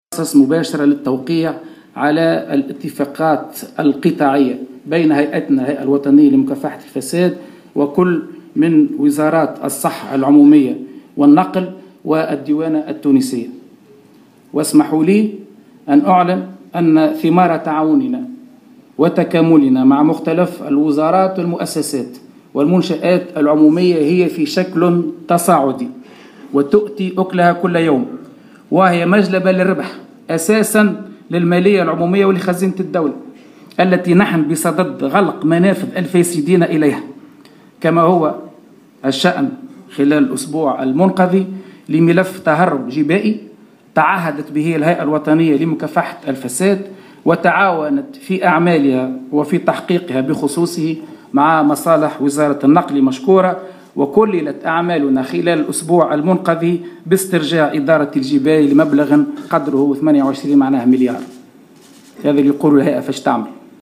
أعلن رئيس الهيئة الوطنية لمكافحة الفساد شوقي الطبيب في تصريح لمراسل الجوهرة "اف ام" اليوم الجمعة 9 ديسمبر 2016 عن جملة من النجاحات التي حققتها الهيئة بالتعاون مع عدد من الوزارات والمنشآت العمومية على غرار وزارتي النقل والصحة والديوانة التونسية.